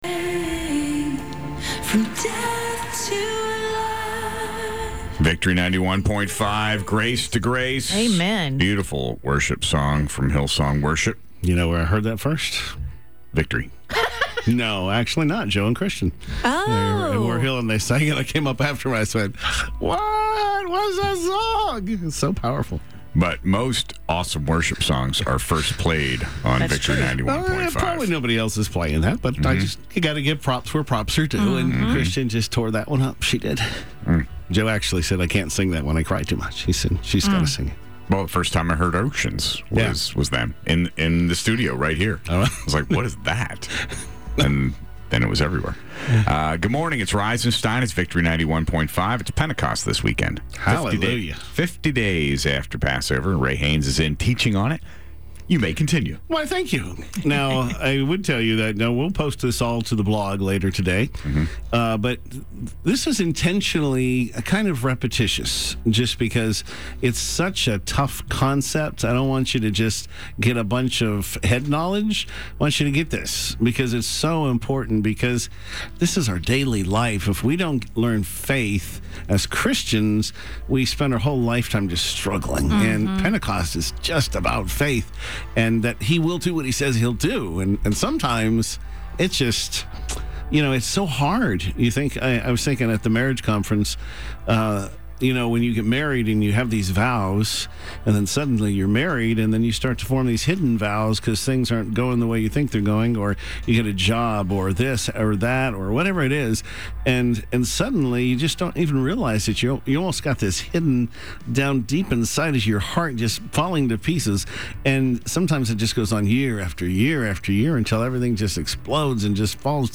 Live on-air clips and teaching notes